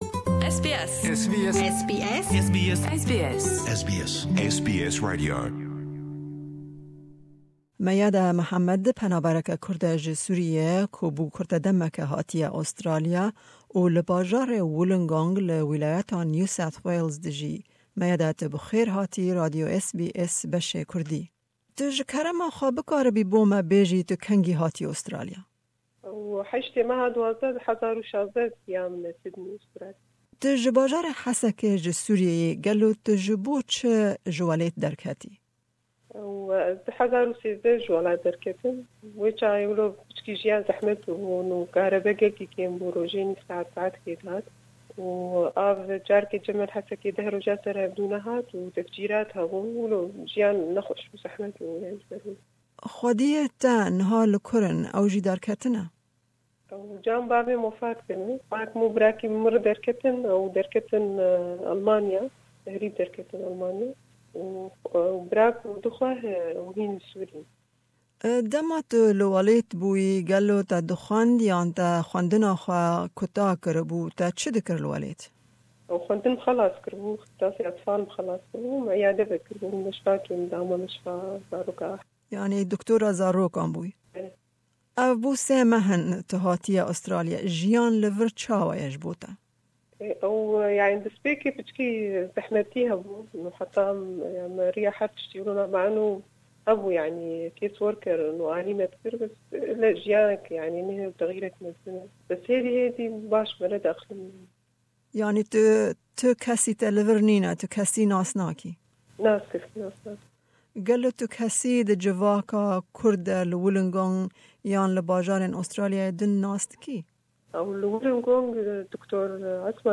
Me hevpeyvînek li ser destpêkirina jiyaneke nuh li Australiya bi wê re bi rê xist.